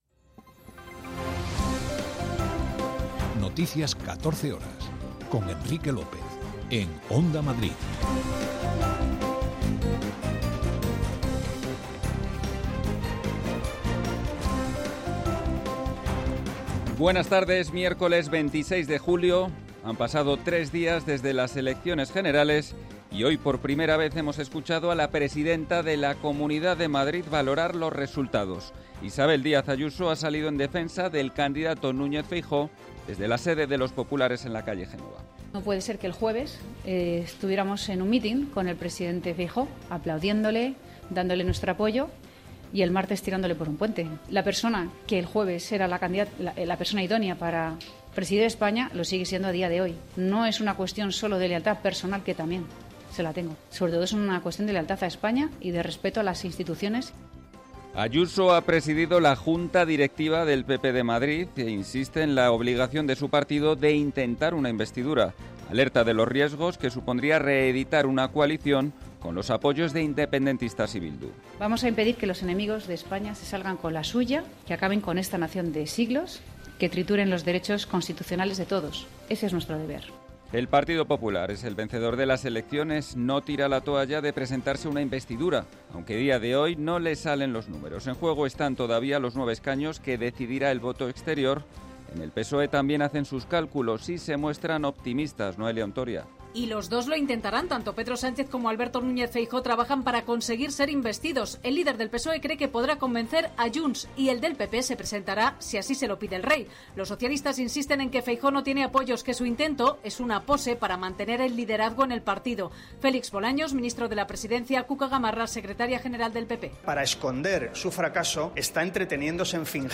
Noticias 14 horas 26.07.2023